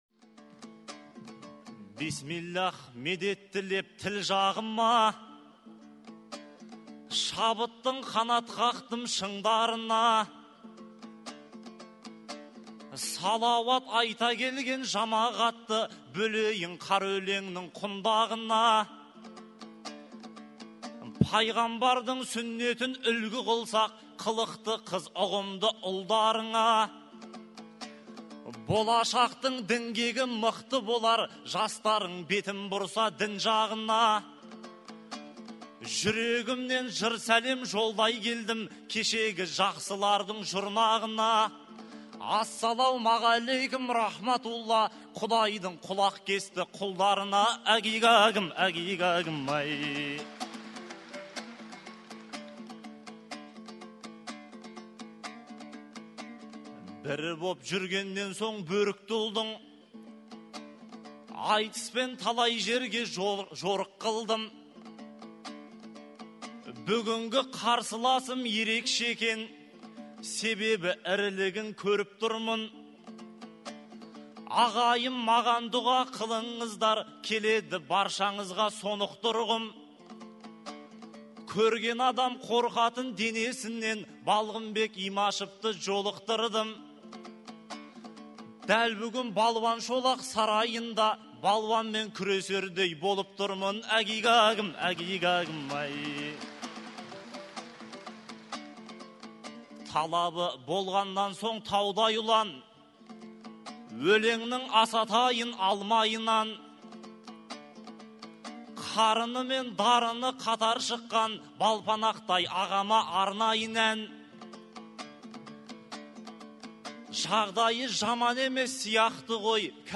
Айтыс өнерін сүйер қауым наурыздың 5-і күні Алматыдағы Балуан Шолақ атындағы спорт сарайында Мұхаммед пайғамбардың туған күні – мәуліт мерекесін атап өтуге жиналды.